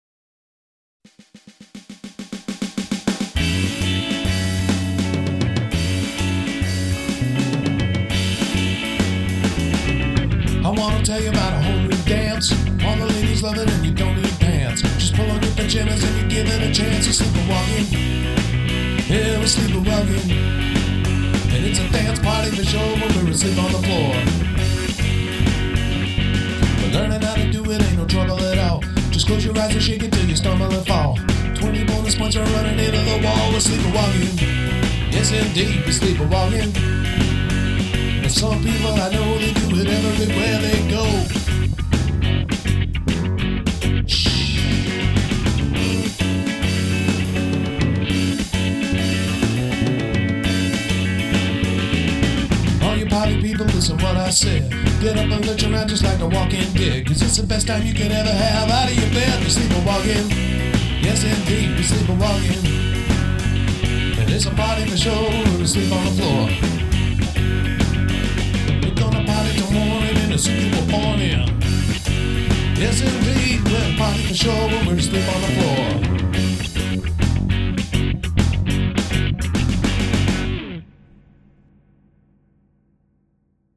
Crescendo